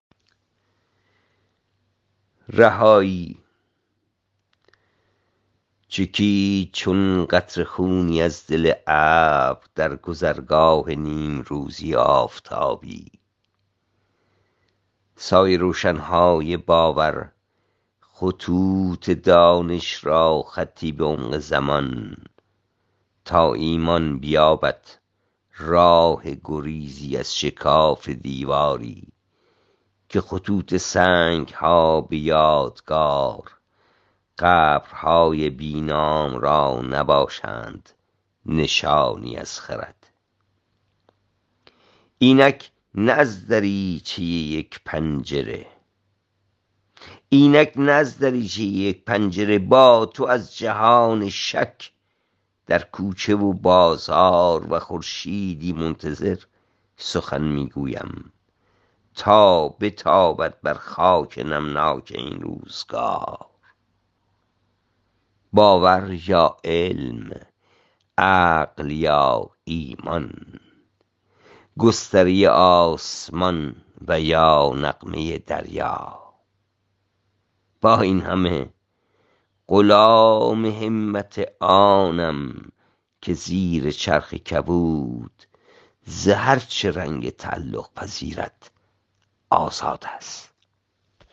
این چکامه را با صدای شاعر بشنیود